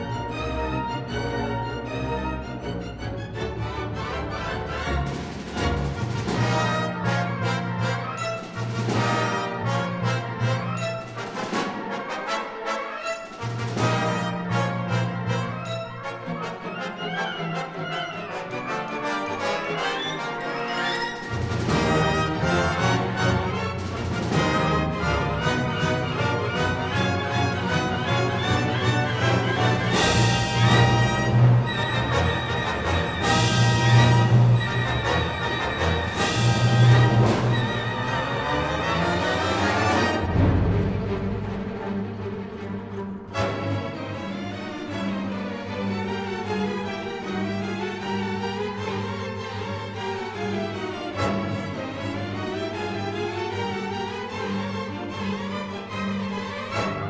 Symphony no.4 in C minor, Op.43 (1936)
Symphony no.10 in E minor, Op.93 (1953)